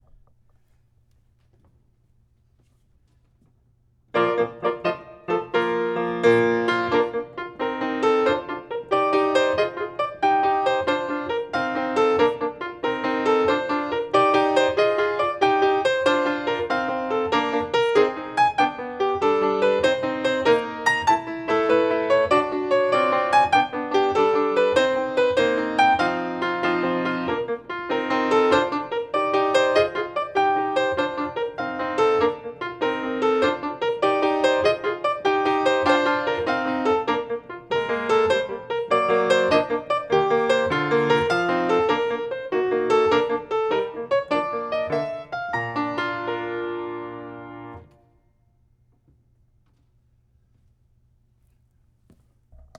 Piano accompaniment
Tempo 95
Rhythm Quick 3/4
Meter 3/4